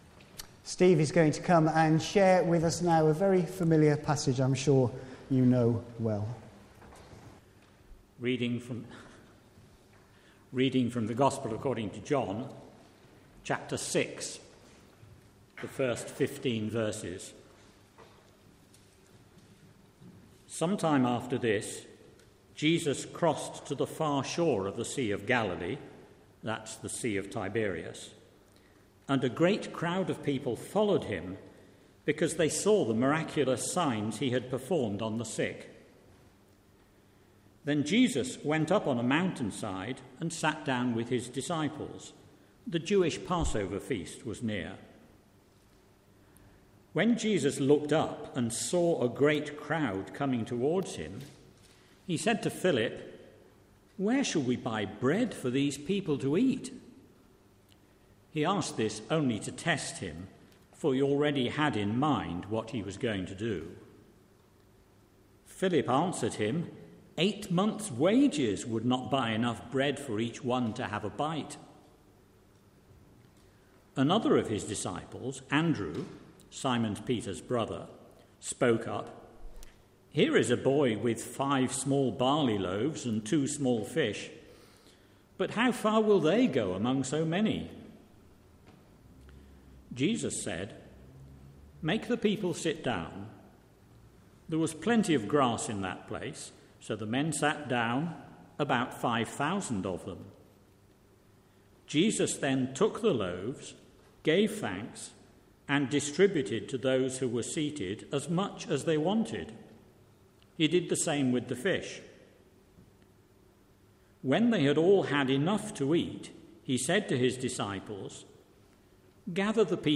A message from the service
From Service: "10.30am Service"